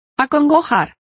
Complete with pronunciation of the translation of afflicting.